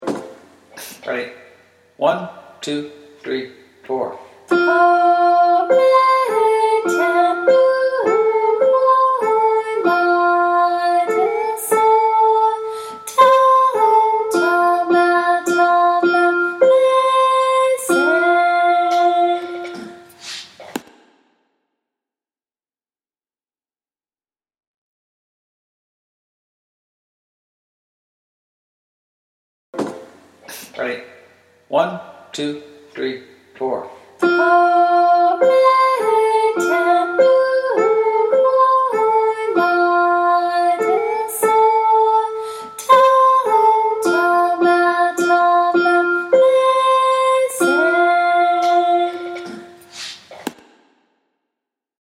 The music for Chorus 3
CloudsChorus3.mp3